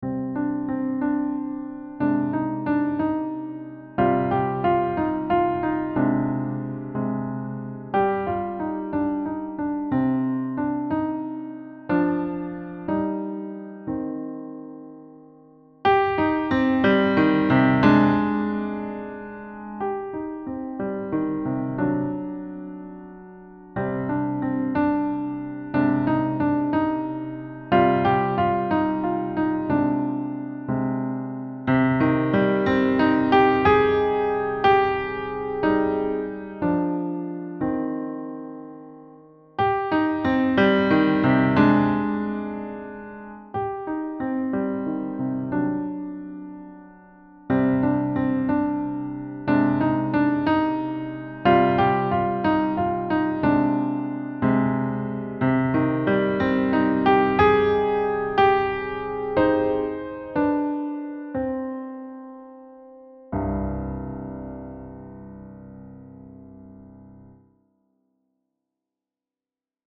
lyrical and expressive piano solo
Key: C natural minor
Time Signature: 3/4
Level: Elementary